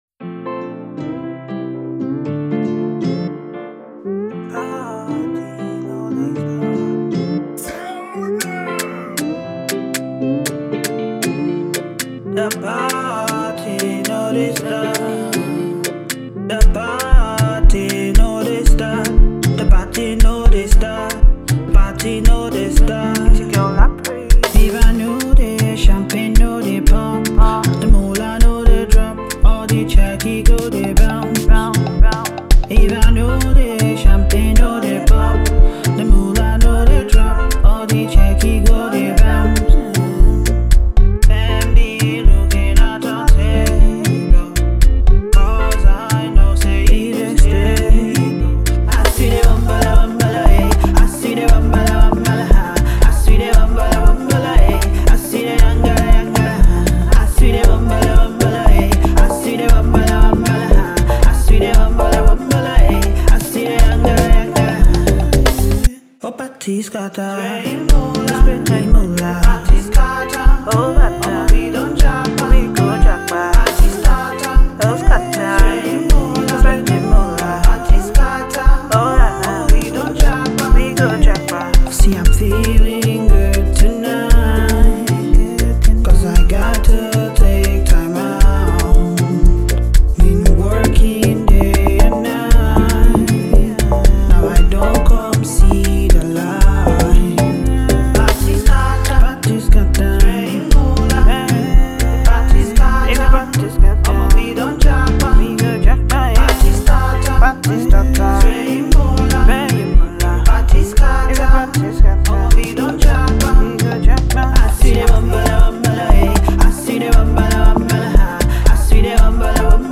Afrobeats
a rhythmic, feel-good anthem
the ultimate dance track
pure groove, energy, and African flavor